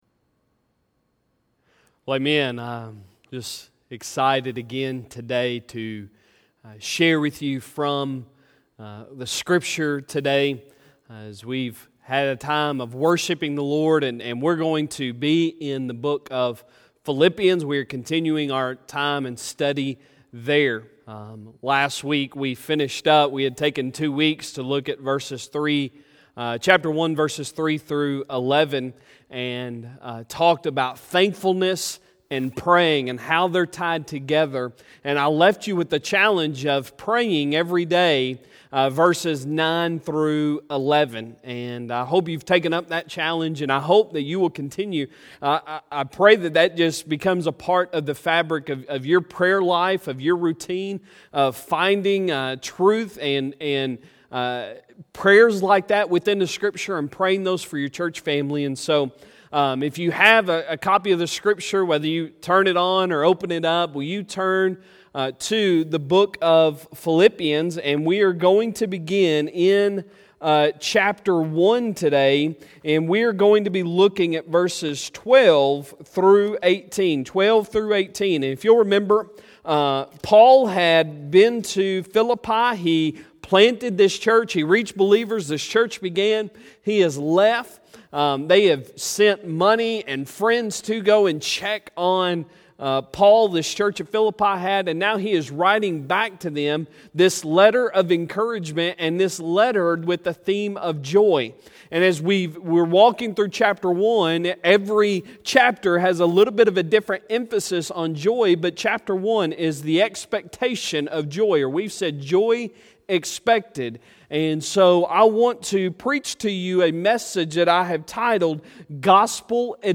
Sunday Sermon May 17, 2020